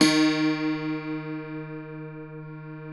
53q-pno07-D1.wav